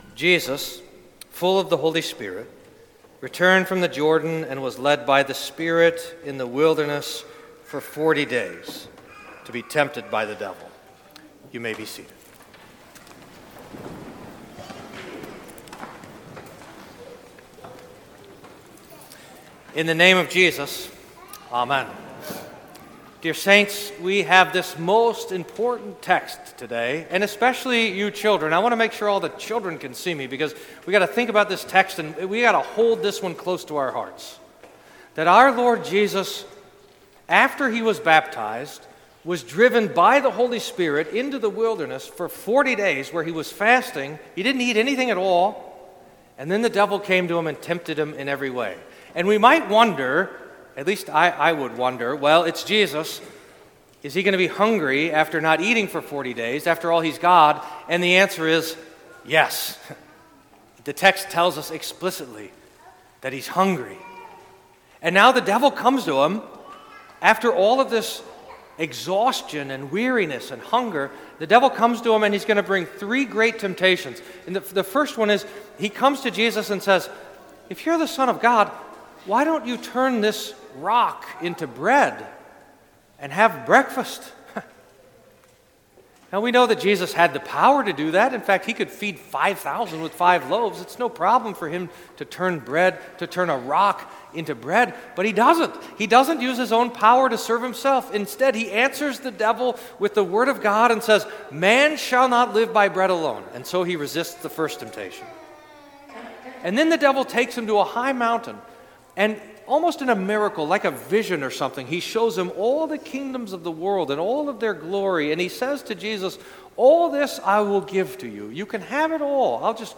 Sermon for First Sunday in Lent